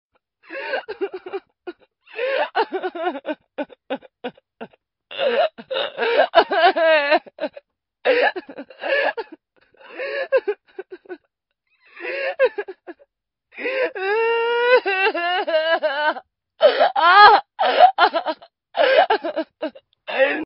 女声伤心哭泣声音效免费音频素材下载